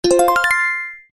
slot-win-.mp3